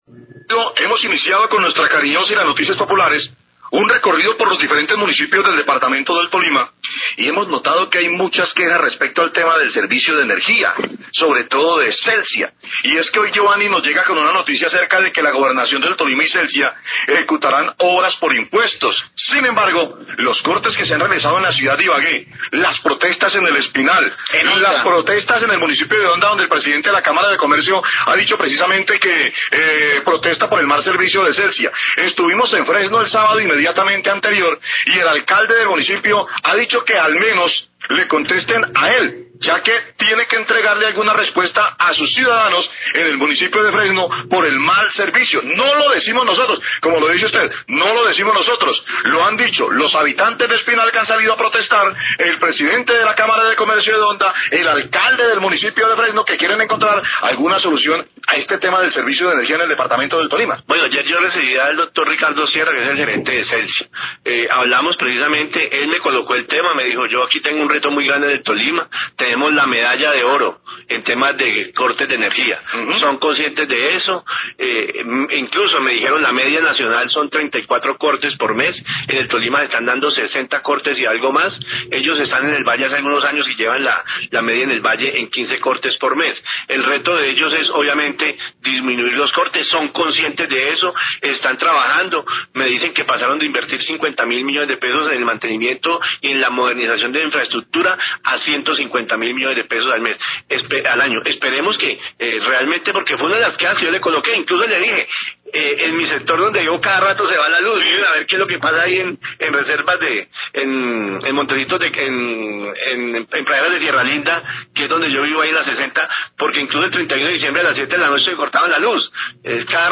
Radio
En una entrevista con el Gobernador del Tolima, Óscar Barreto, denuncian los cortes de energía continuos en el departamento y a raíz de esa queja, cuestionan el programa obras por impuestos que se ejecutará la gobernación con Celsia.